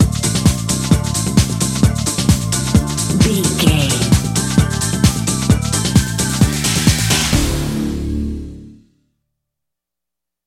Aeolian/Minor
DOES THIS CLIP CONTAINS LYRICS OR HUMAN VOICE?
WHAT’S THE TEMPO OF THE CLIP?
synthesiser
drum machine
Eurodance